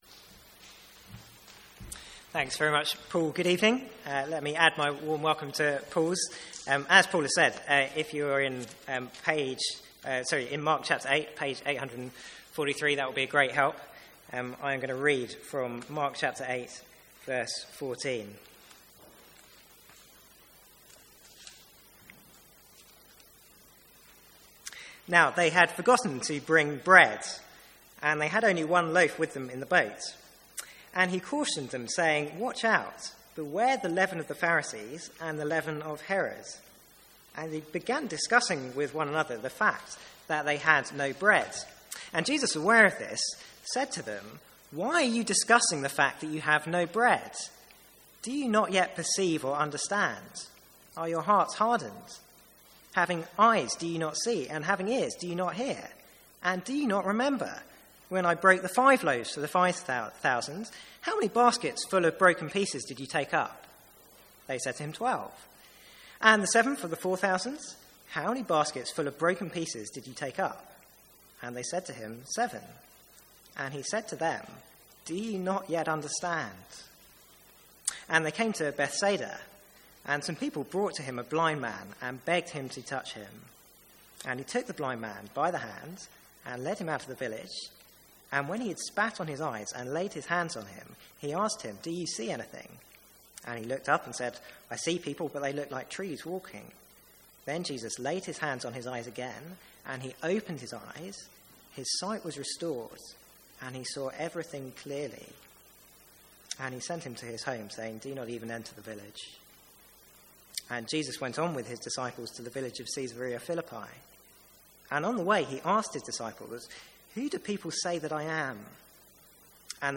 Sermons | St Andrews Free Church
From the Sunday evening series on the Five Points of Calvinism.